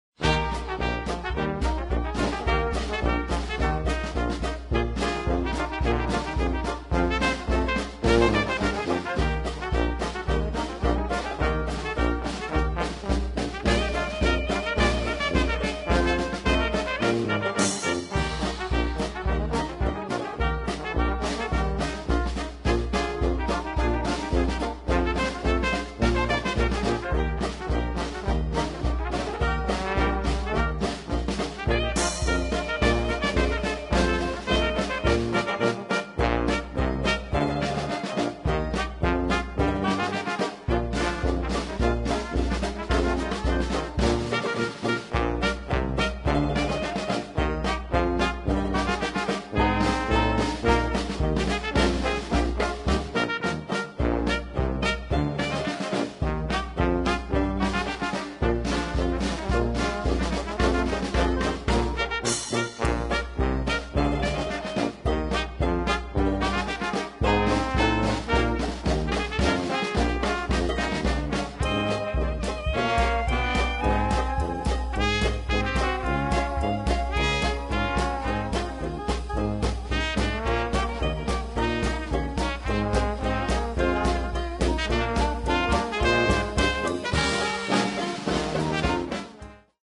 Nineteen Nineteen Rag (Anno 1919) è un ragtime a tempo di marcia.